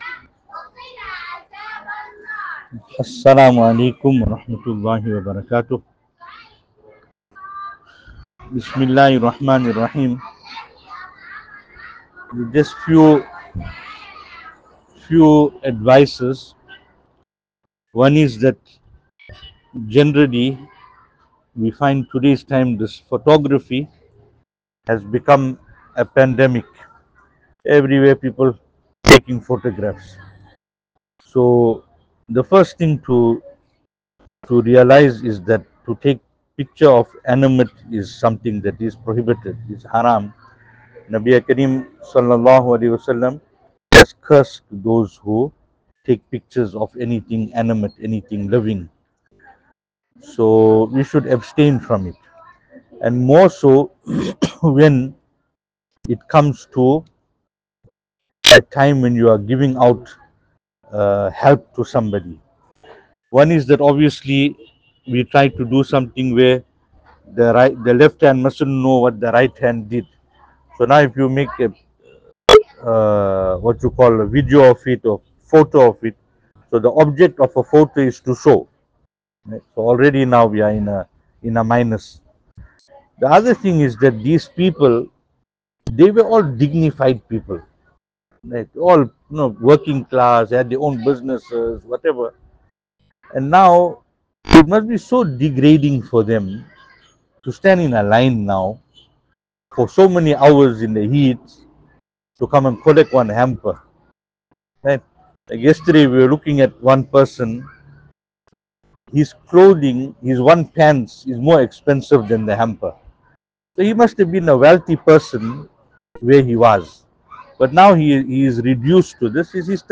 2024-09-30 Bring Our Lives Closer to Sunnah & Appreciate Our Ulema E Kiraam Venue: Albert Falls , Madressa Isha'atul Haq Service Type: Zikr